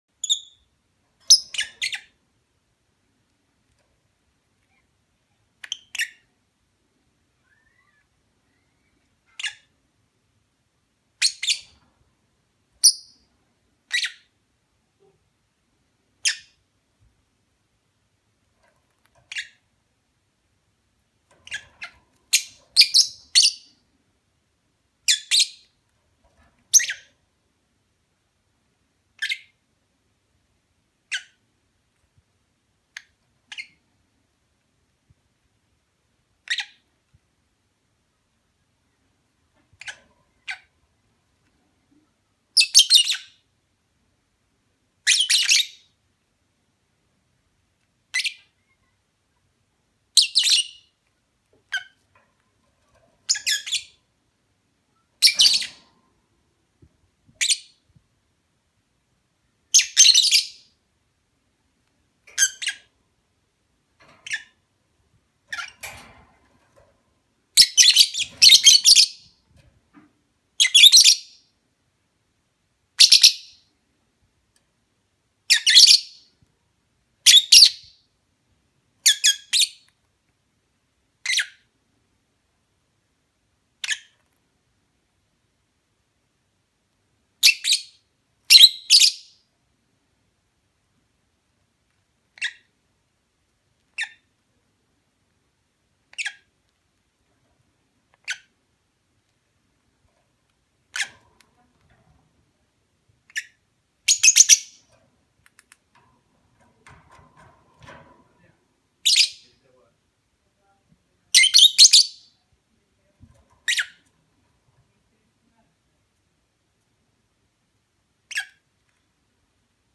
جلوه های صوتی
دانلود صدای مرغ عشق از ساعد نیوز با لینک مستقیم و کیفیت بالا